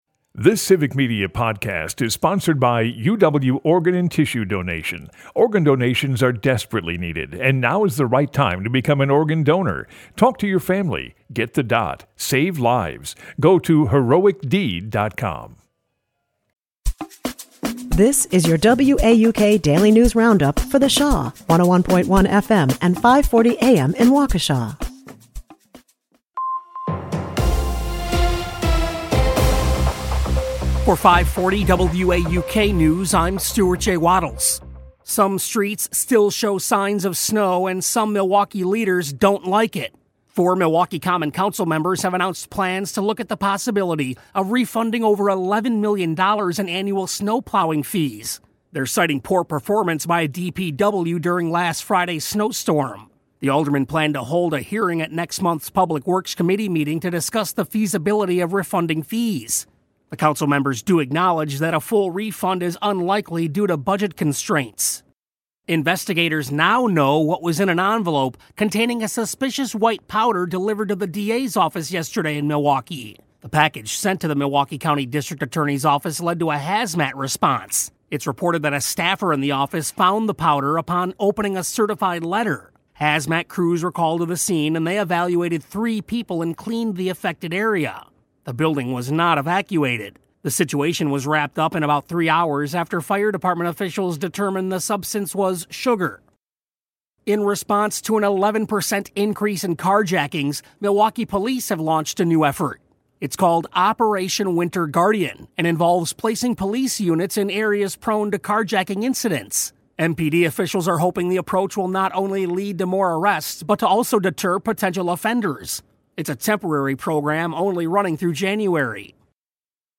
The WAUK Daily News Roundup has your state and local news, weather, and sports for Milwaukee, delivered as a podcast every weekday at 9 a.m. Stay on top of your local news and tune in to your community!